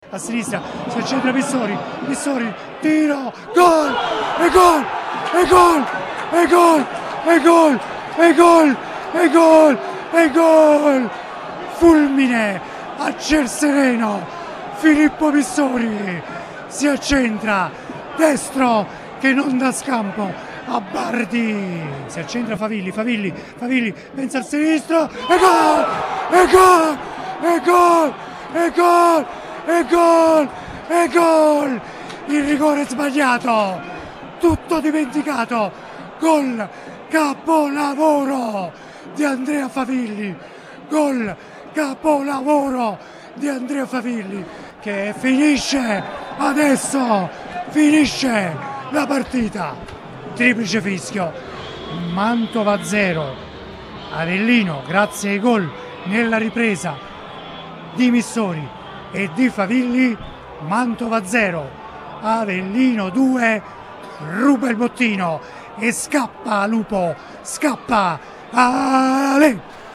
Riviviamo insieme le emozioni dello stadio Martelli